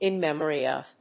アクセントin mémory of…